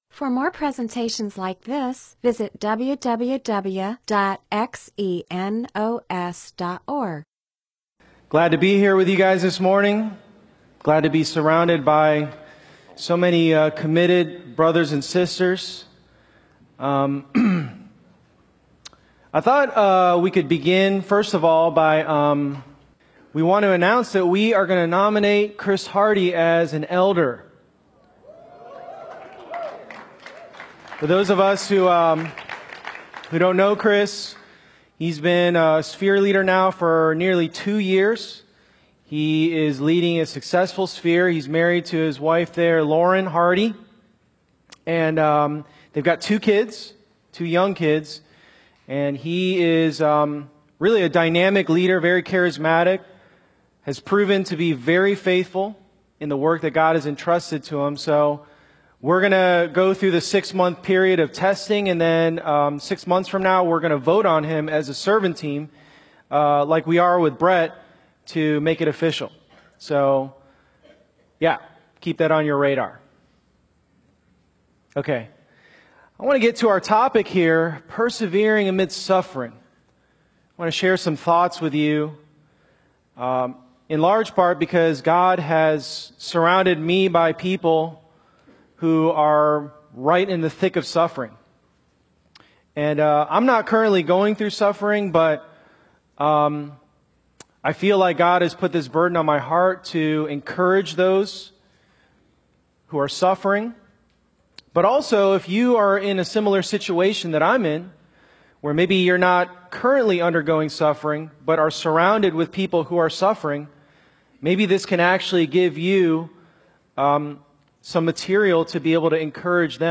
MP4/M4A audio recording of a Bible teaching/sermon/presentation about James 1:3-4.